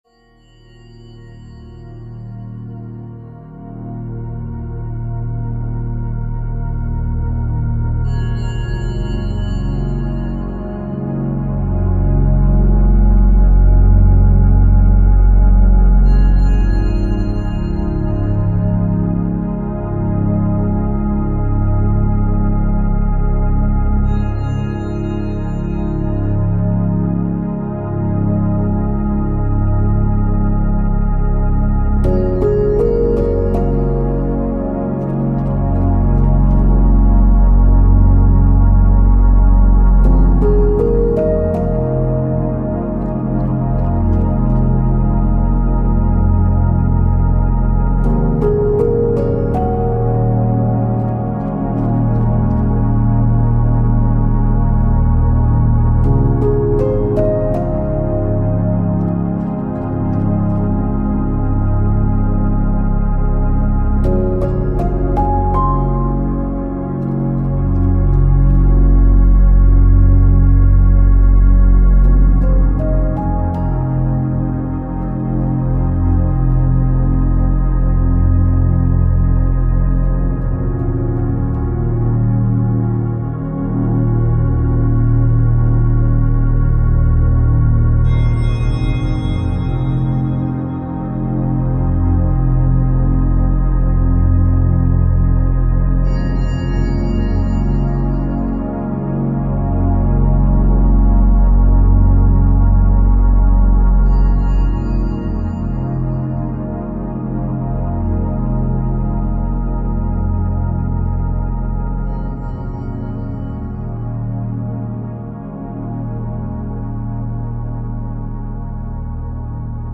The track is decidedly balanced and calm.